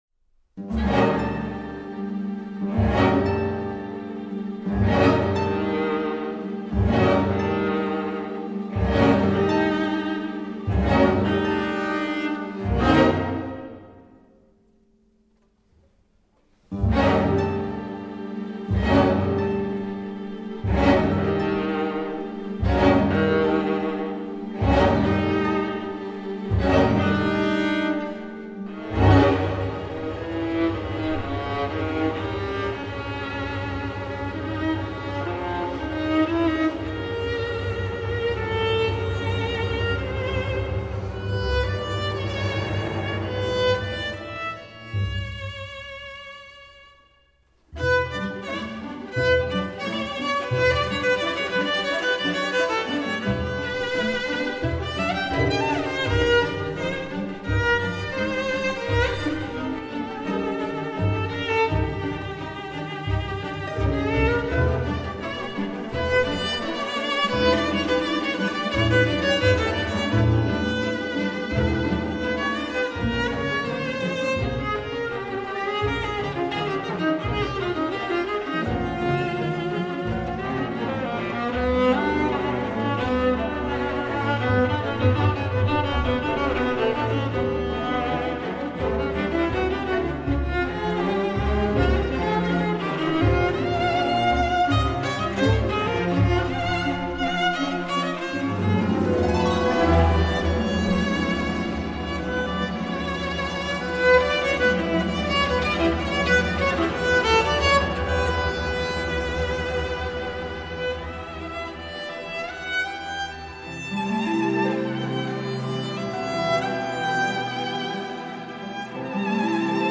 Ηχογράφηση στο Studio C